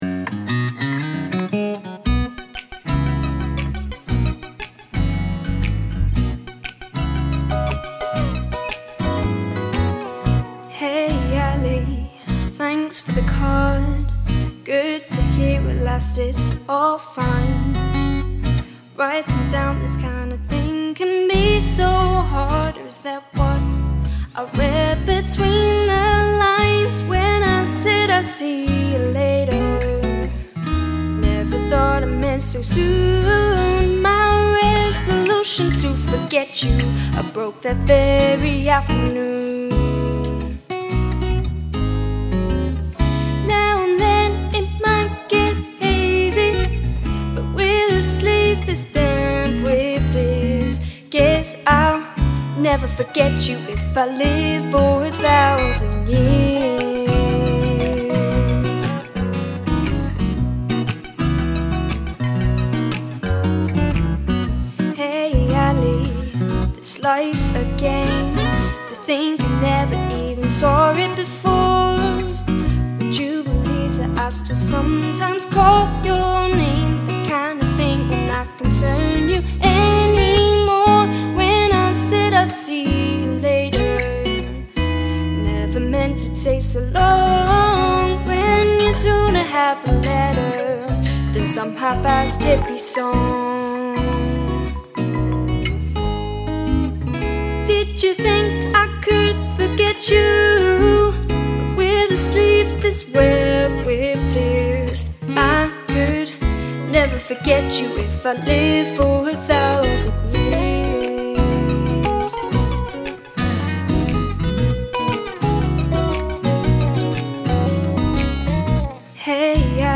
There's also an acoustic Version